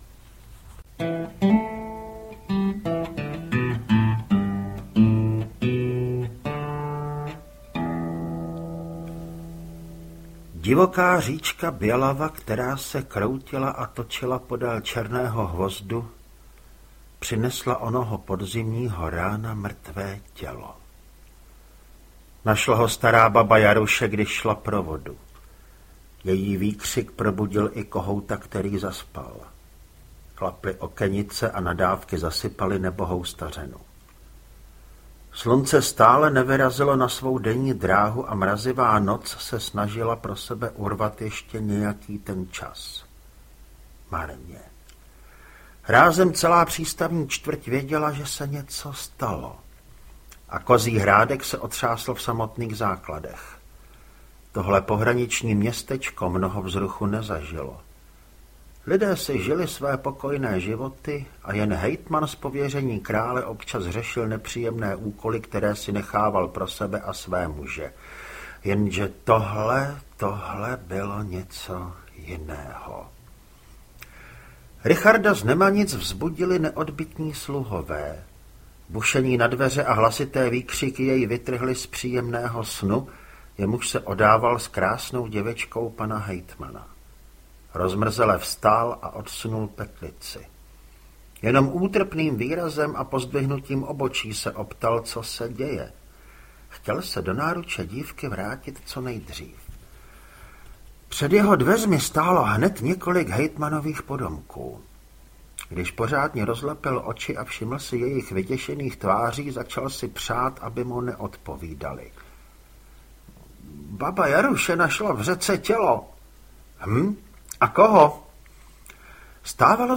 Stín Černého hvozdu audiokniha
Ukázka z knihy